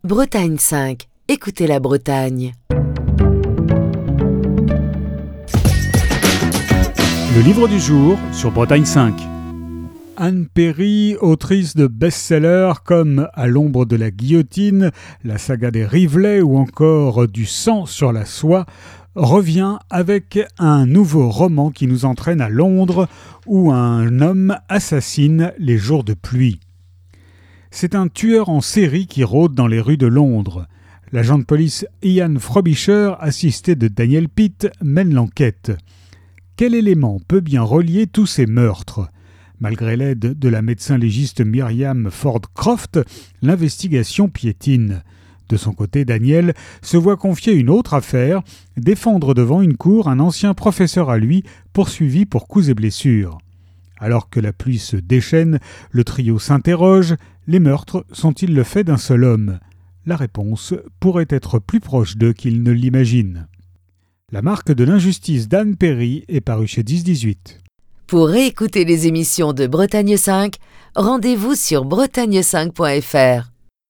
Chronique du 6 octobre 2022.